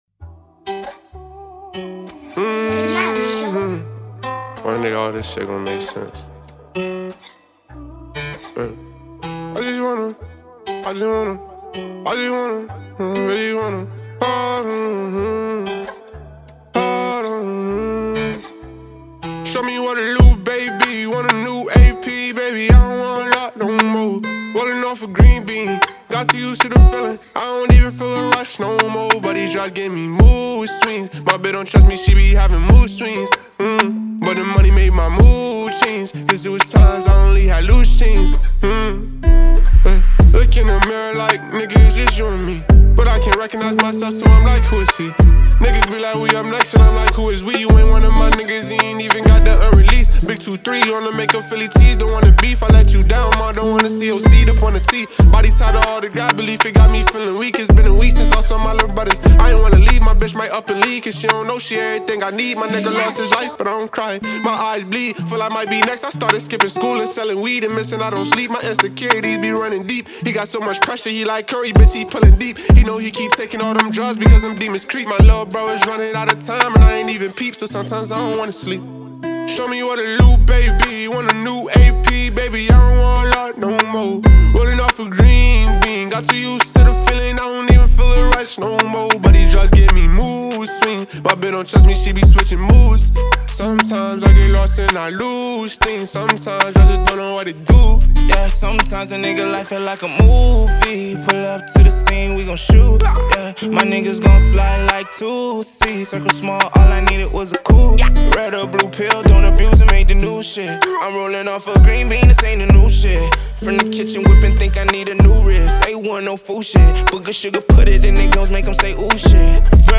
smoothly produced banger